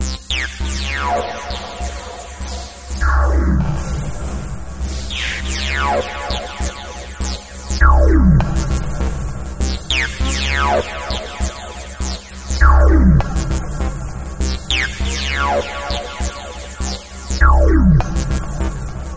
Descarga de Sonidos mp3 Gratis: sintetizador 5.